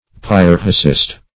pyrrhicist - definition of pyrrhicist - synonyms, pronunciation, spelling from Free Dictionary Search Result for " pyrrhicist" : The Collaborative International Dictionary of English v.0.48: Pyrrhicist \Pyr"rhi*cist\, n. (Gr.
pyrrhicist.mp3